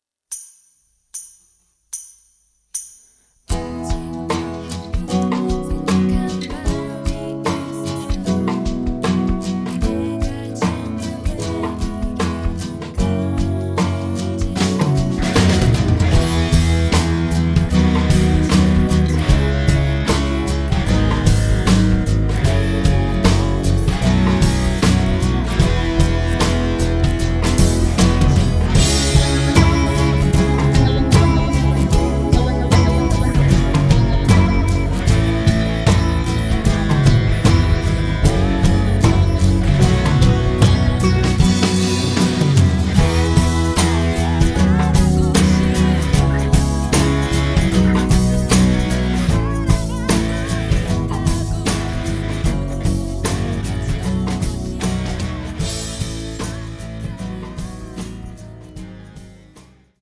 음정 -1키 3:26
장르 가요 구분 Voice Cut